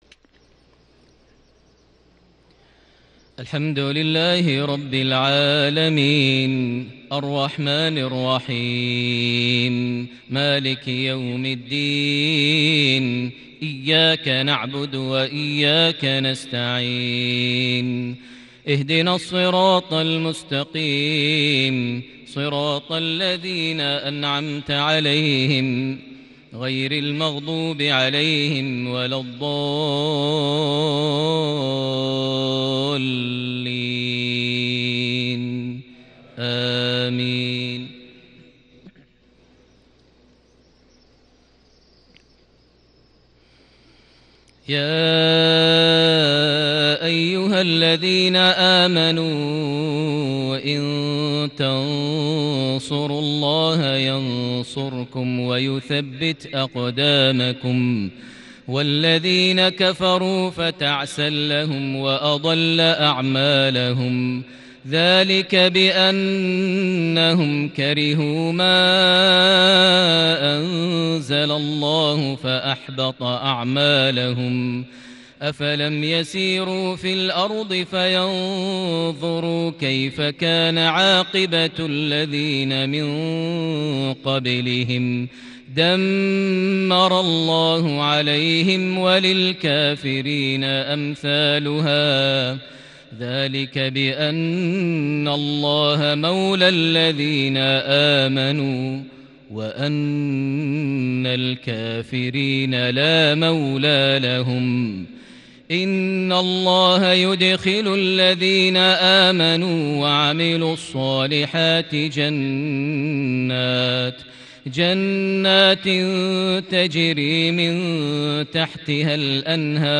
صلاة العشاء ٥ رجب ١٤٤١هـ سورة محمد ٧-١٩ > 1441 هـ > الفروض - تلاوات ماهر المعيقلي